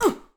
SFX_Battle_Vesna_Defense_04.wav